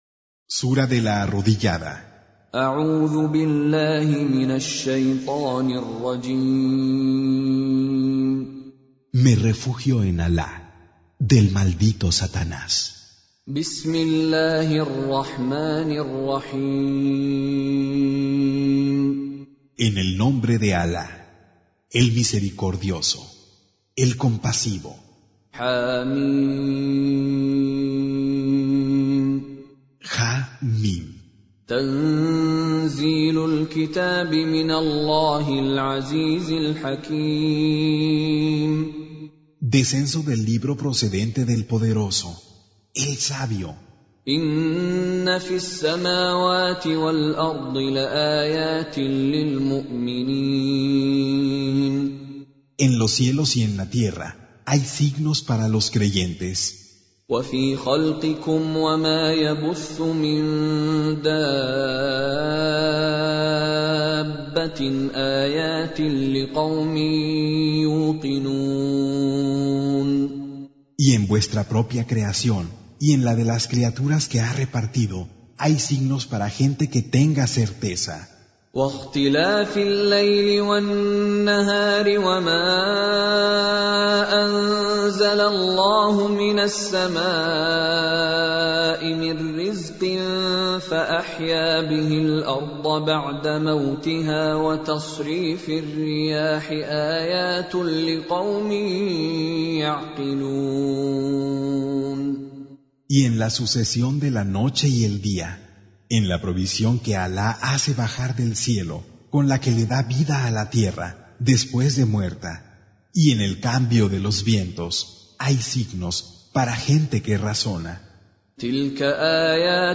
Surah Repeating تكرار السورة Download Surah حمّل السورة Reciting Mutarjamah Translation Audio for 45. Surah Al-J�thiya سورة الجاثية N.B *Surah Includes Al-Basmalah Reciters Sequents تتابع التلاوات Reciters Repeats تكرار التلاوات